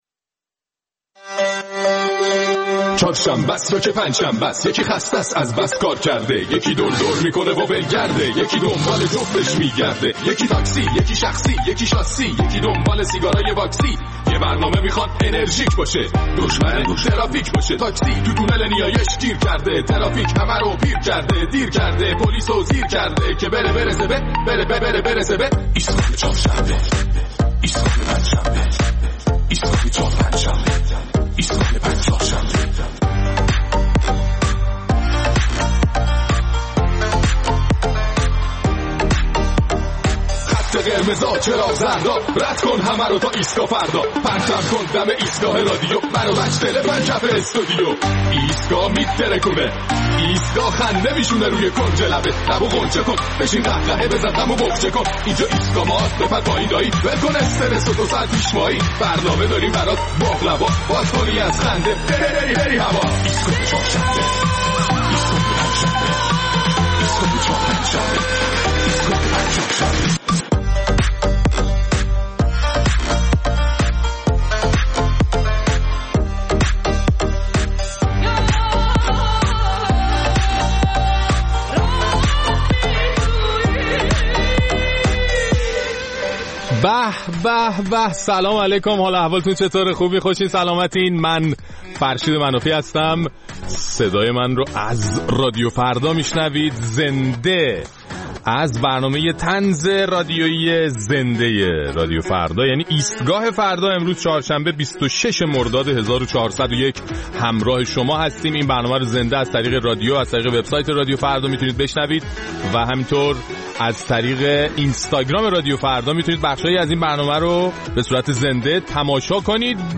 در این برنامه نظرات شنوندگان ایستگاه فردا را در مورد اختلالات وسیع صورت گرفته در اینترنت کشور و حرف‌های وزیر مربوطه در مورد بالا رفتن توقعات مردم می‌شنویم.